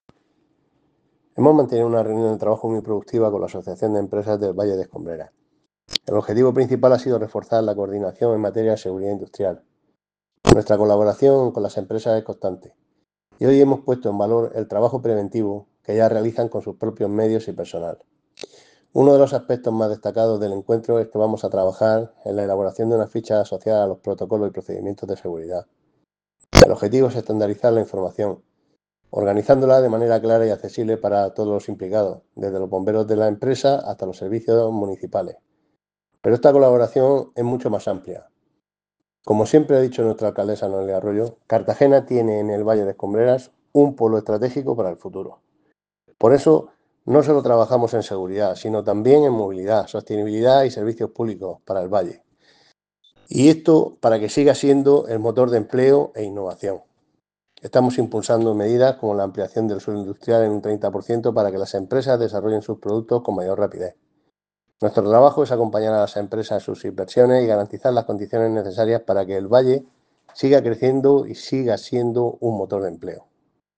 Declaraciones de José Ramón Llorca.